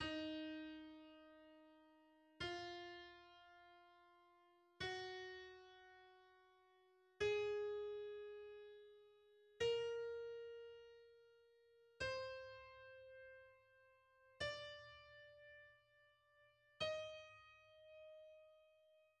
Gama dis-moll w odmianie doryckiej (z VI i VII stopniem podwyższonym o półton w stosunku do gamy dis-moll naturalnej):